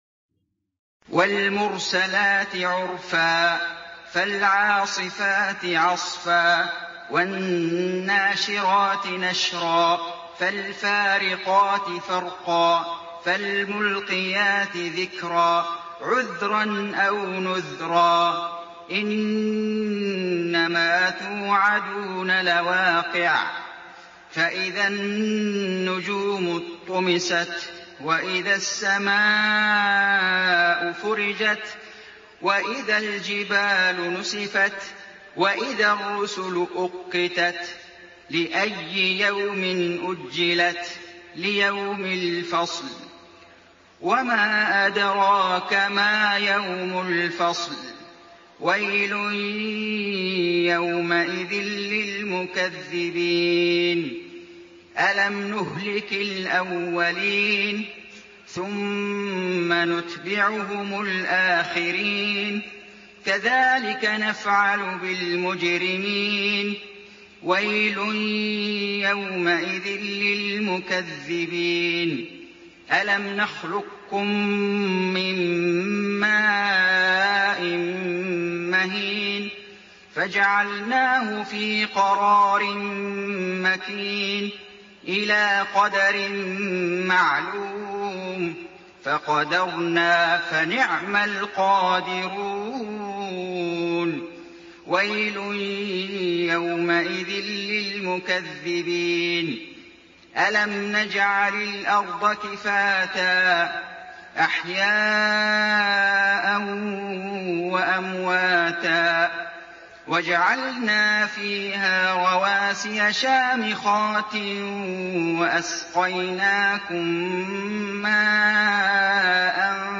سورة المرسلات > السور المكتملة للشيخ فيصل غزاوي من الحرم المكي 🕋 > السور المكتملة 🕋 > المزيد - تلاوات الحرمين